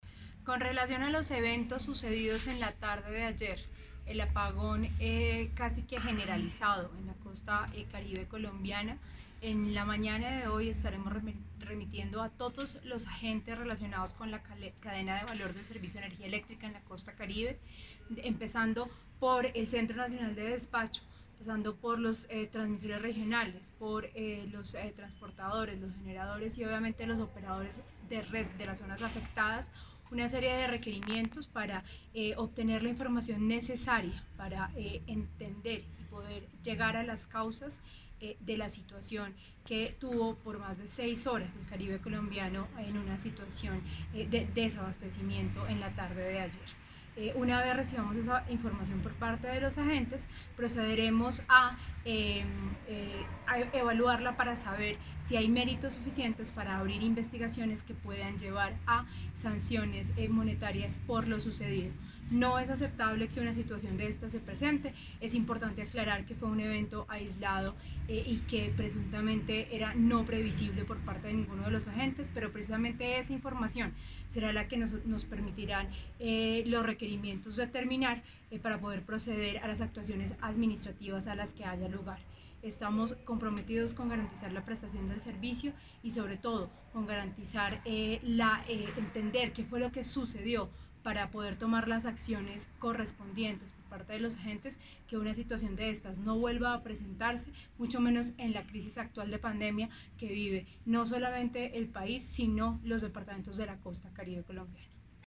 Audio - Declaraciones Superintendente Natasha Avendaño García